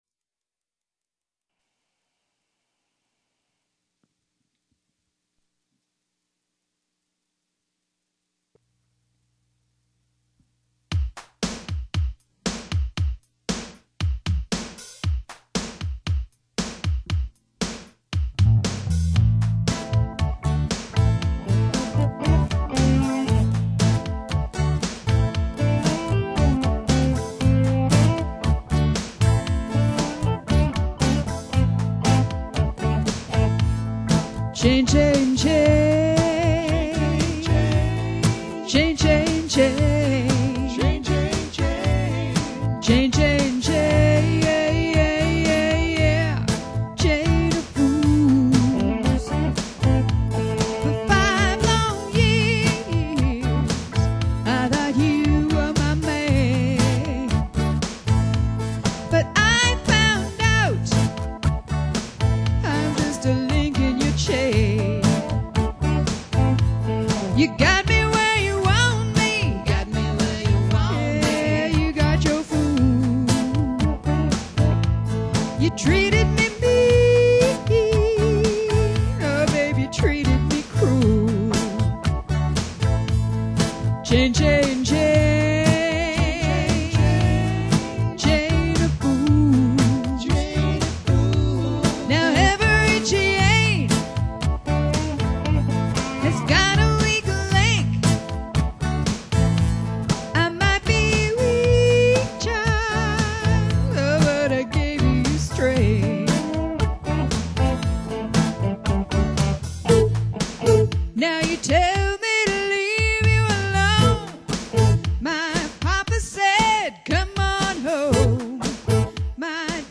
This is the ultimate variety band.